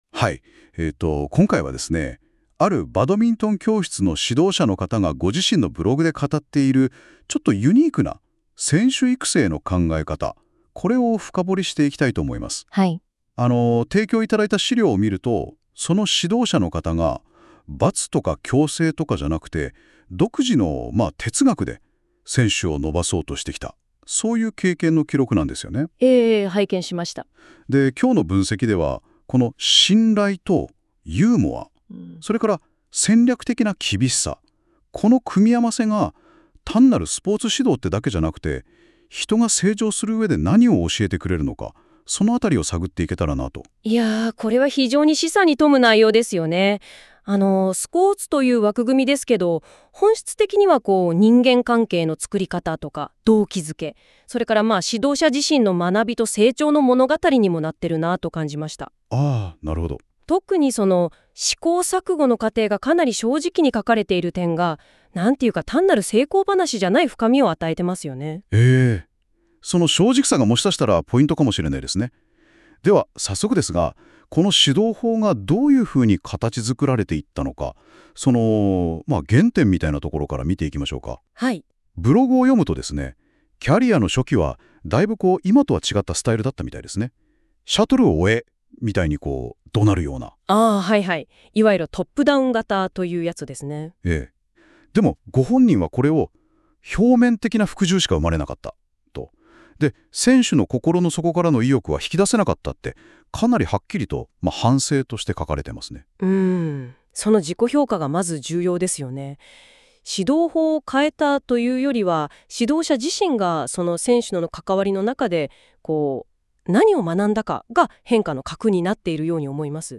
信頼・ユーモア・厳しさ・問いかけ：バドミントン指導者が語る「自律型人材育成」の極意.m4a